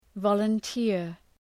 {,vɒlən’tıər}